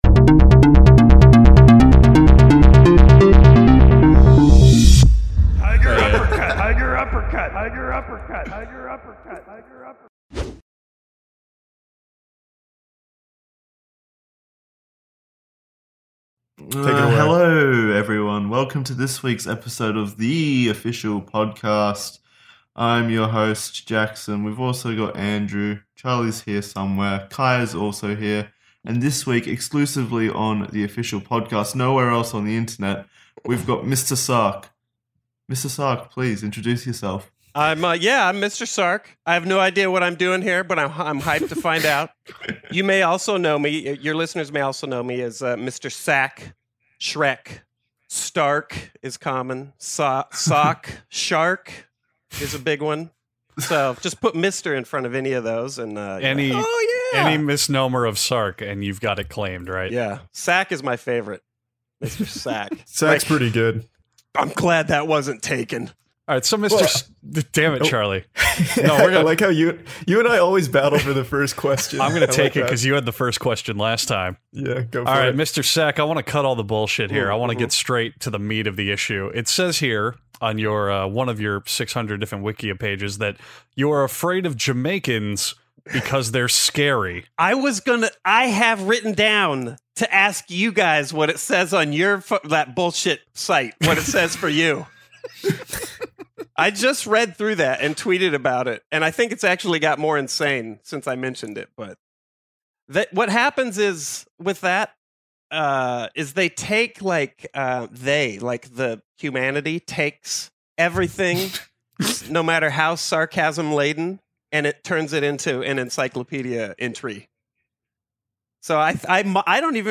Five close man friends gather around to talk about how evil Machinima is.